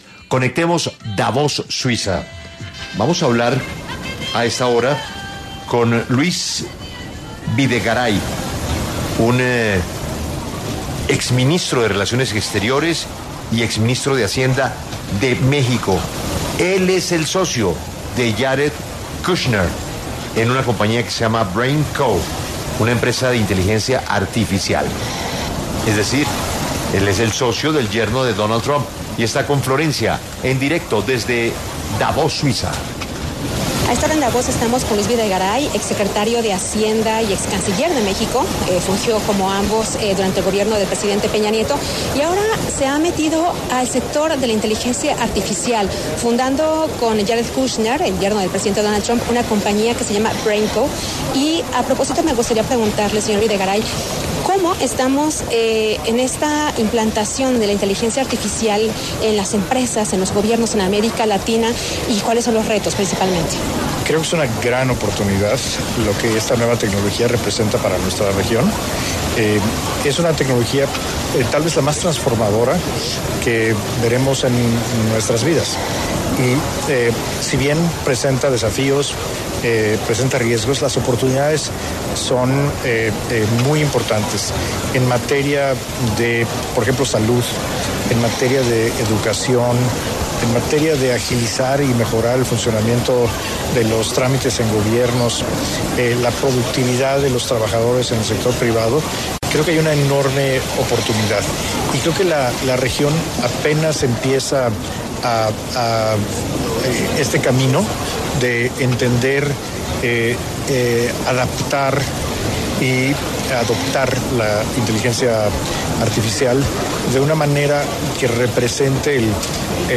Luis Videgaray, exsecretario de Hacienda, excanciller de México y socio del yerno del presidente de Estados Unidos, Donald Trump, conversó con 6AM W acerca de la implantación de la inteligencia artificial en América Latina.